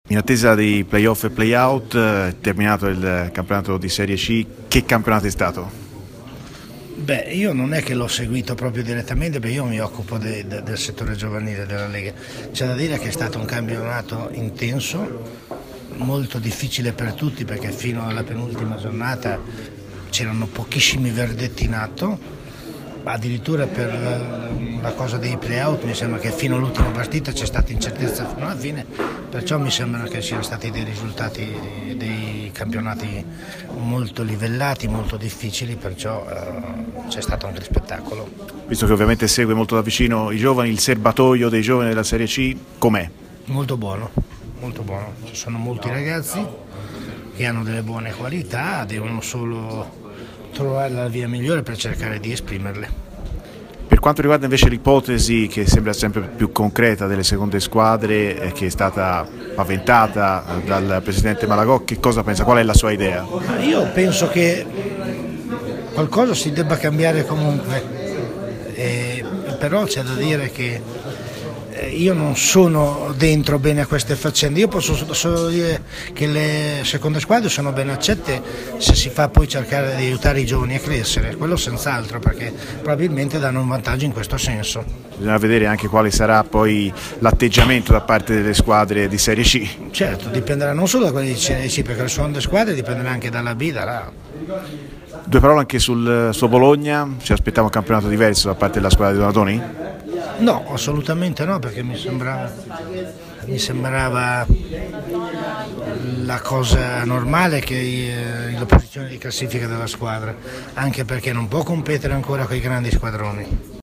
al Premio Maestrelli a Fiuggi